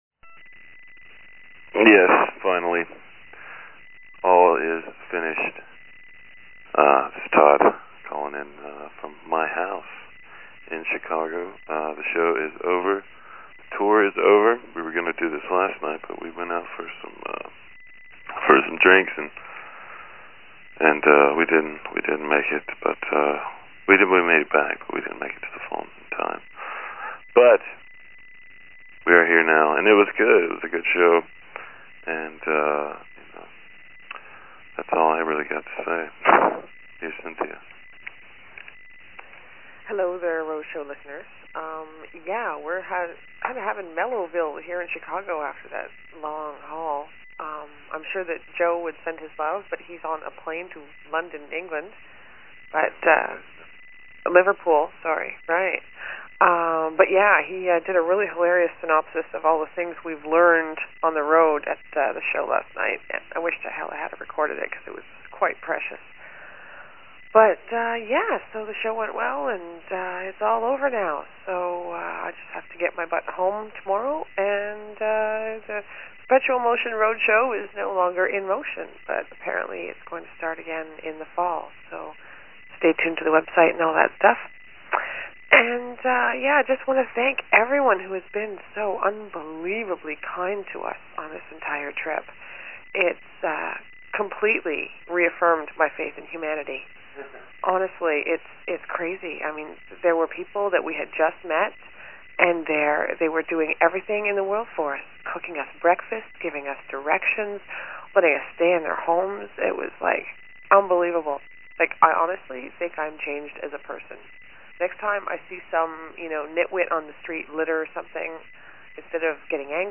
Every day they were on the road, one of them called and left a message about something that happened that day, and they were posted below.